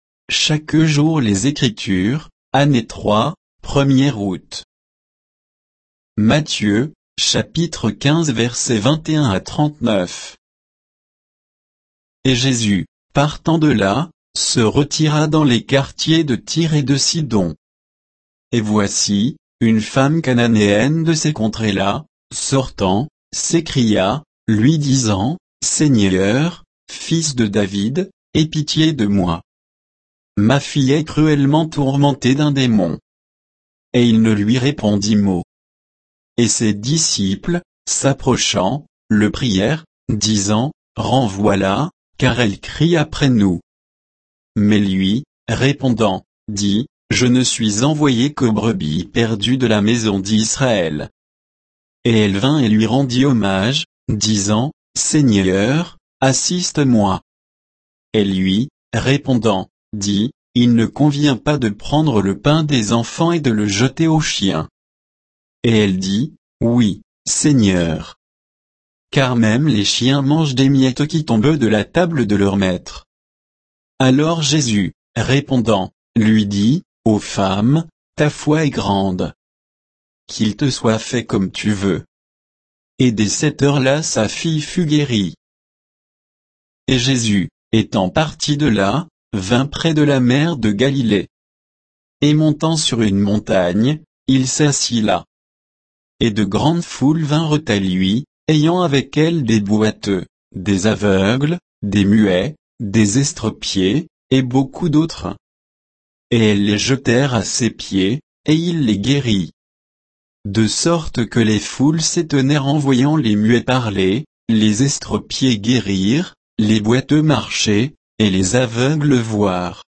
Méditation quoditienne de Chaque jour les Écritures sur Matthieu 15